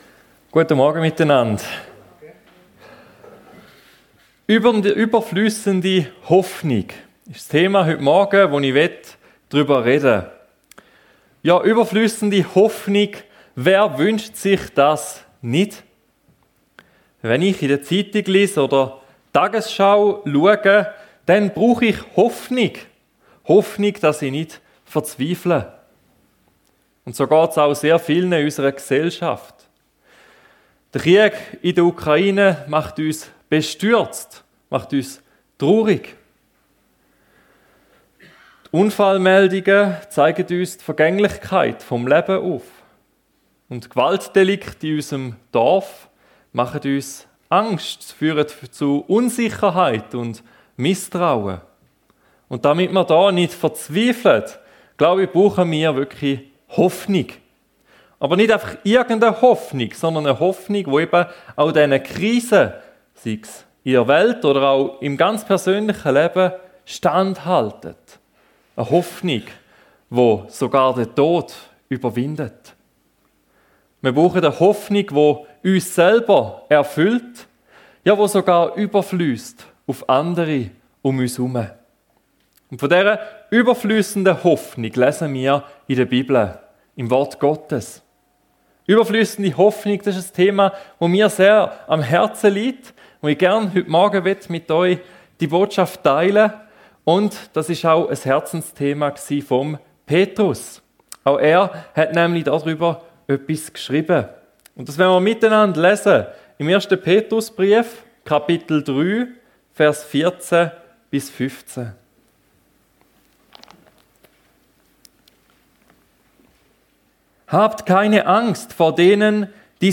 Überfliessende Hoffnung ~ FEG Sumiswald - Predigten Podcast